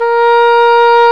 Index of /90_sSampleCDs/Roland L-CDX-03 Disk 1/CMB_Wind Sects 1/CMB_Wind Sect 2
WND ENGHRN0D.wav